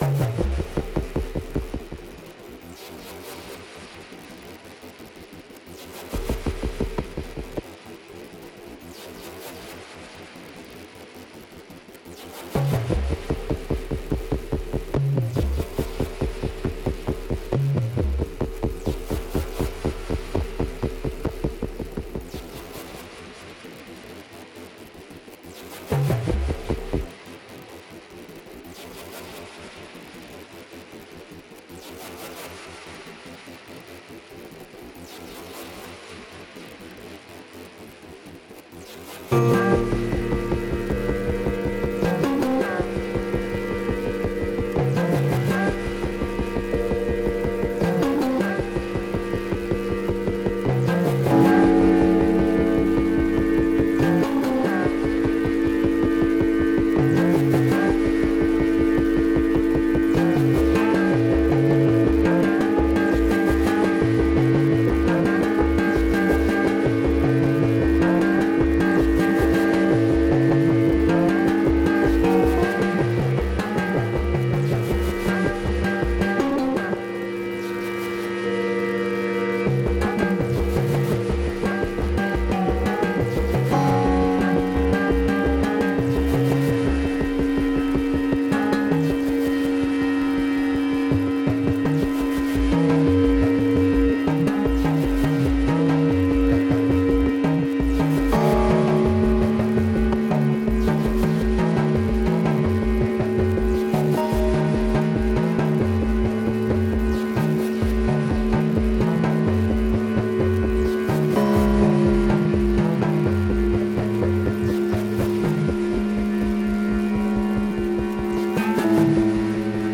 It is really very odd / experimental and nothing special, but it is interesting to add voices and just see where you gonna land instead of having a chord progression and then try to find a melody for it.
(funny also, the master tempo is automated, so the whole thing meanders between slow and fast through the whole track )